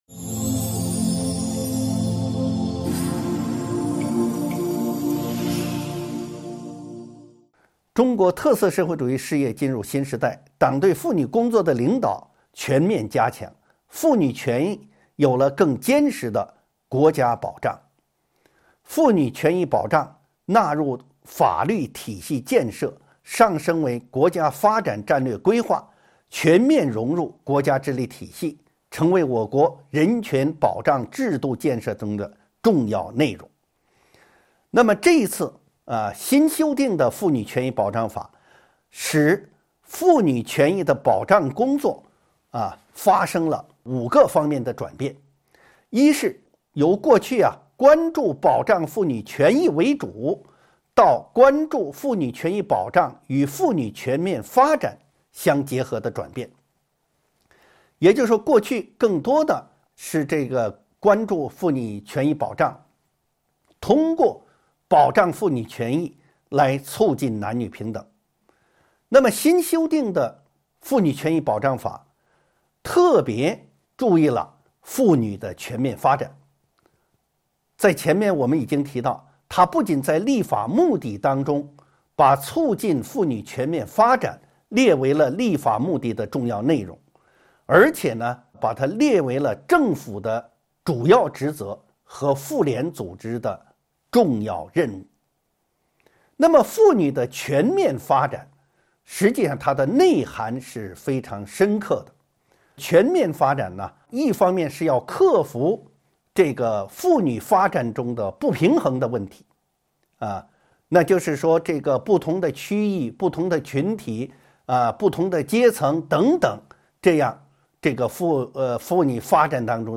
音频微课：《中华人民共和国妇女权益保障法》4.新修订提出的新要求